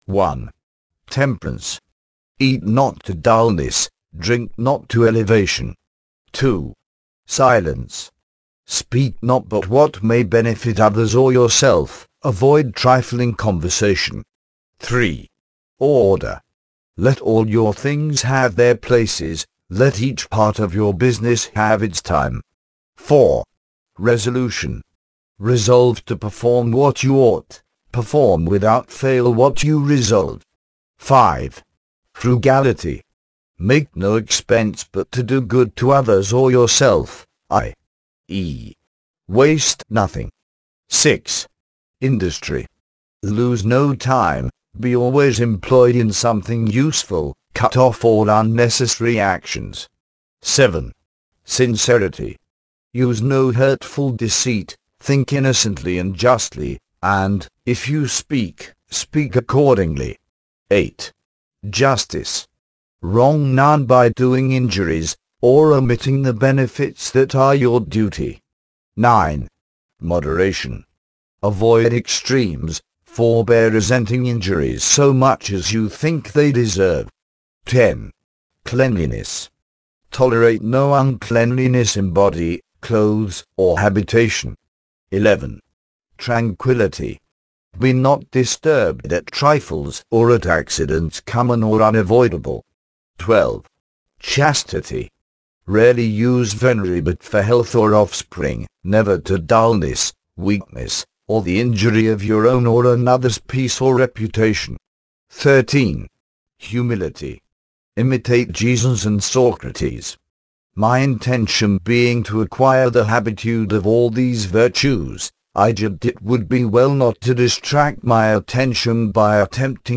Daily Reading